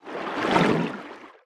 Sfx_creature_seamonkey_swim_slow_03.ogg